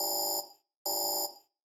Alarm4.ogg